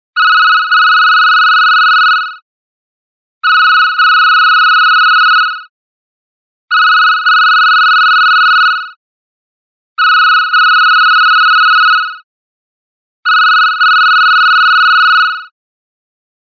Category: Old Phone Ringtones